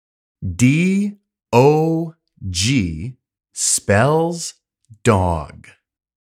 単語の読み方・発音